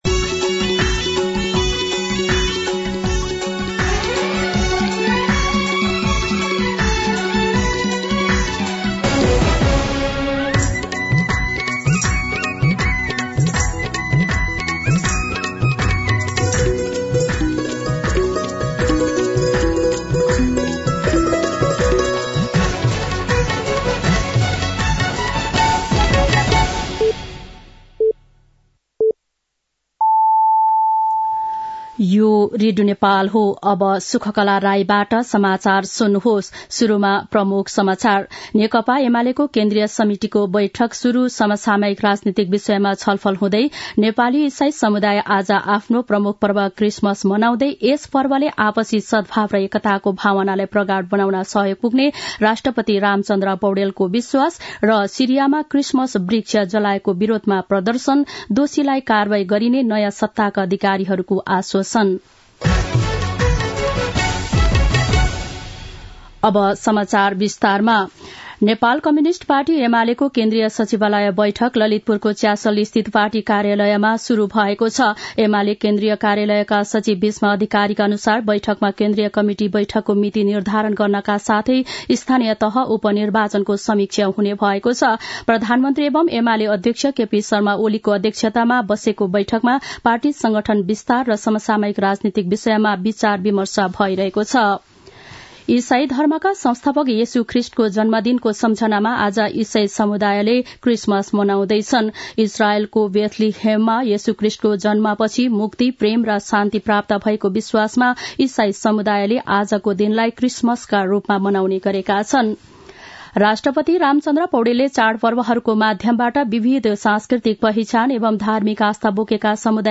दिउँसो ३ बजेको नेपाली समाचार : ११ पुष , २०८१
3-pm-nepali-news-1-15.mp3